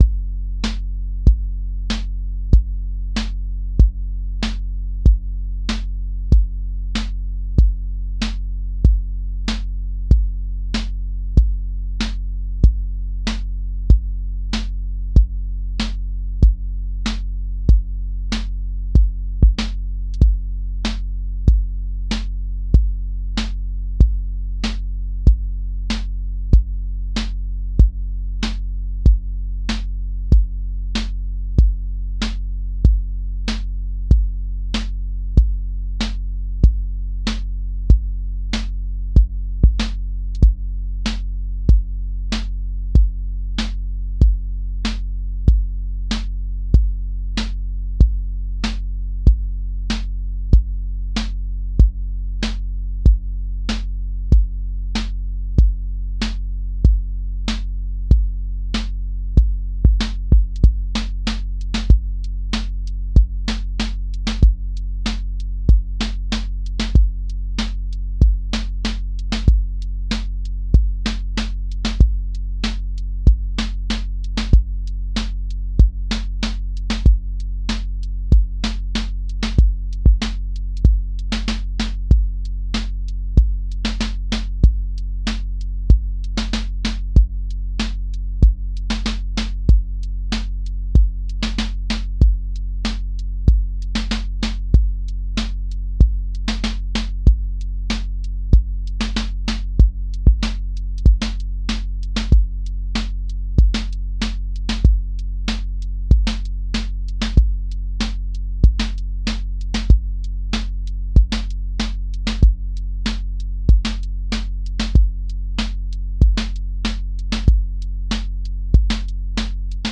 QA Listening Test boom-bap Template: boom_bap_drums_a
• voice_kick_808
• voice_snare_boom_bap
• voice_hat_rimshot
• voice_sub_pulse
A 120-second boom bap song with recurring patterns, a lifted bridge, and a grounded return that feels like an actual song rather than one loop